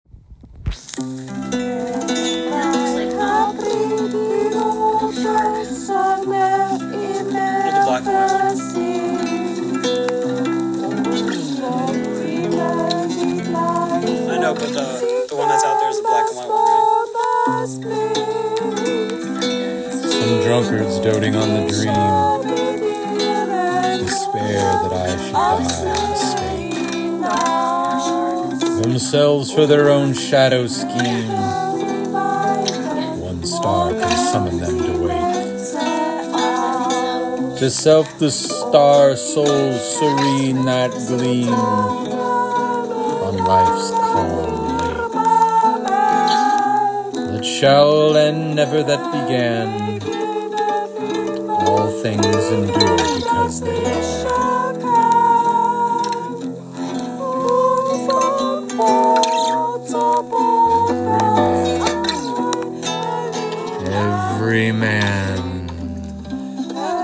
Court Hill Equinox is a sound piece made on the equinox at Court Hill, where I live. It was recorded and assembled on that day, in that place.
The work is a simple expression of life as it was occurring at the moment of balance between seasons. There is no attempt to dramatize or resolve the sound. It documents presence, duration, and change as they happened.